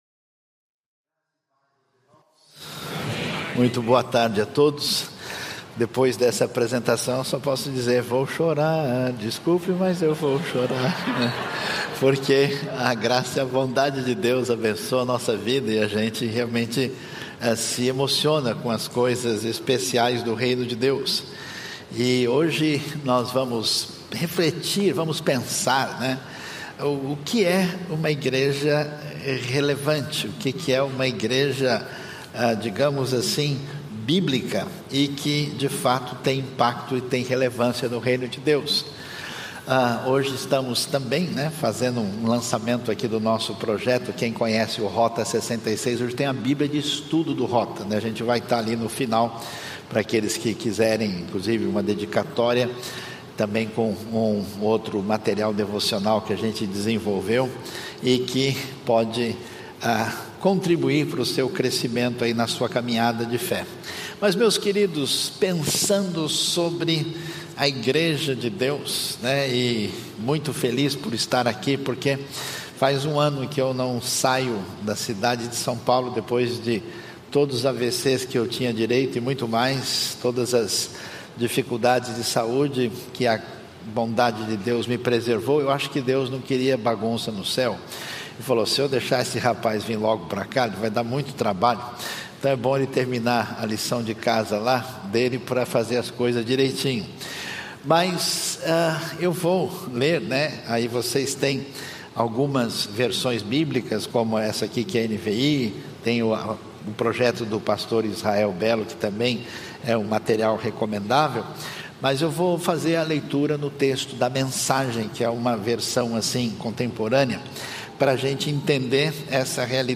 Conferência Teológica #6
Igreja Batista do Recreio